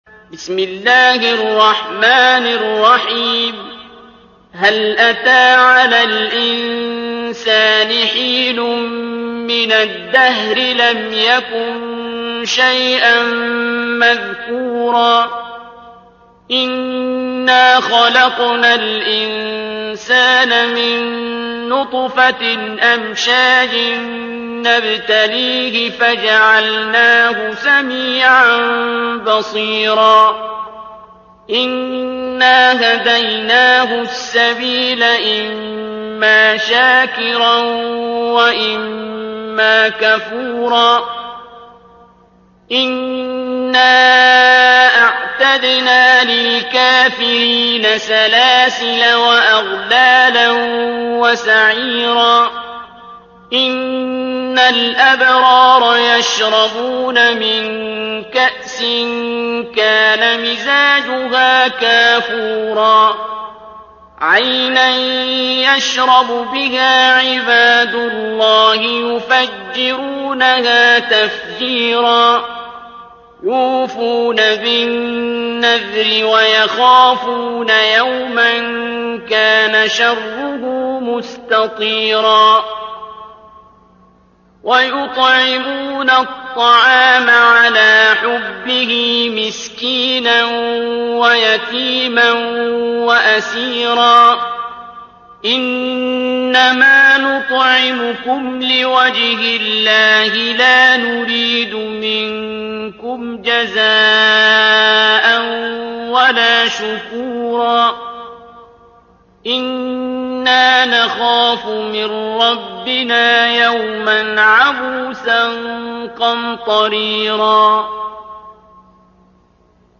ترتيل